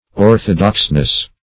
Search Result for " orthodoxness" : The Collaborative International Dictionary of English v.0.48: Orthodoxness \Or"tho*dox`ness\, n. The quality or state of being orthodox; orthodoxy.